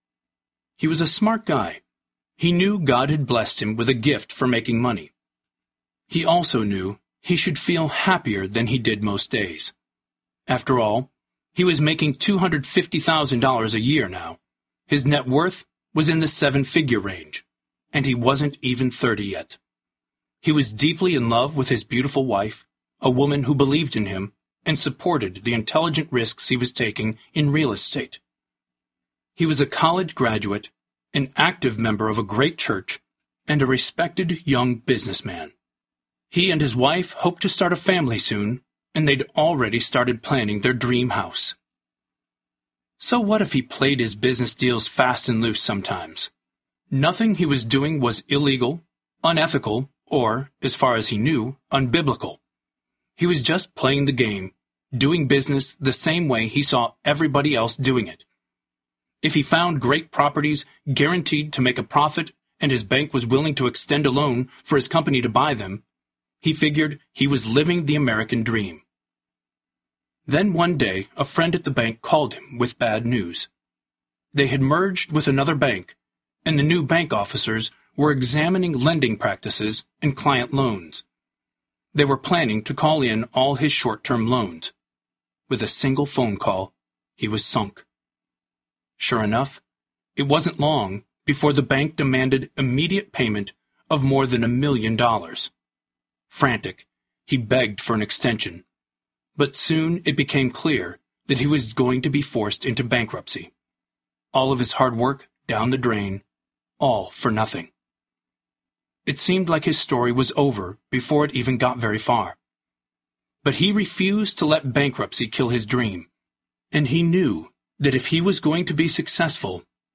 Divine Direction Audiobook
5.3 Hrs. – Unabridged